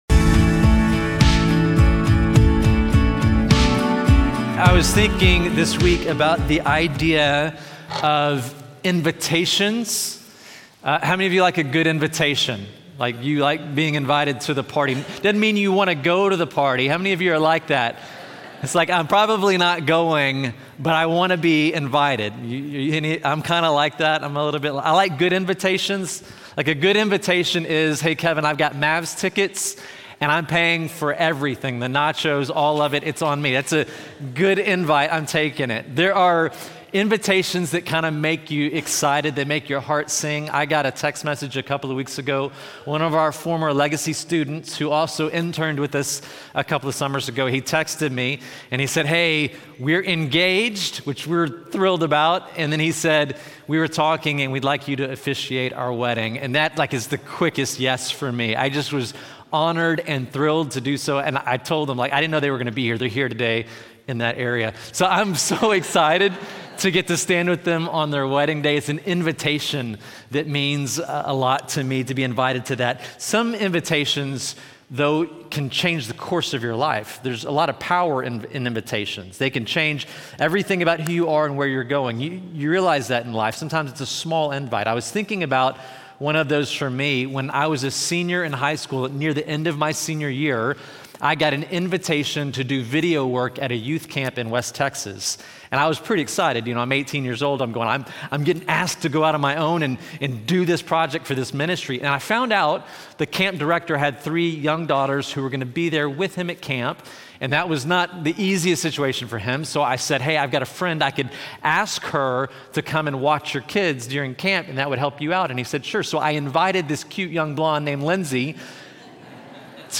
This sermon from Luke 14:1-24 uses Jesus’ parable of the Great Dinner to show that God’s Kingdom isn’t a burden to carry, it’s a banquet we’re invited to enjoy.